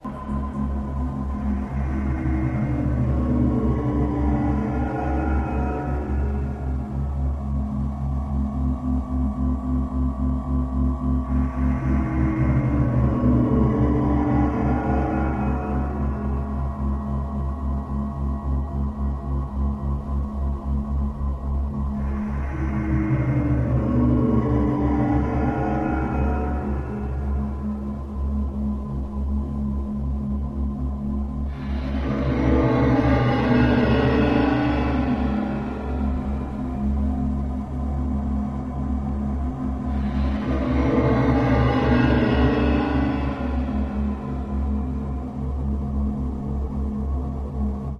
Nuclear Sub-Hollow metal tone with steady electronic noise